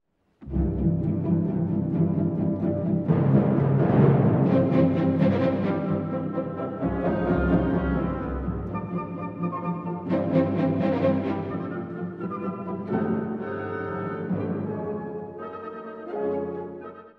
このスケルツォは、古典派のような軽妙さではなく、むしろ荒々しく粗削り
切り込むようなリズムと短い動機が積み重なり、音楽に緊迫感を与えます。
リズムのアクセントも独特で、どこか「地に足がつかない」不安定さが印象的です。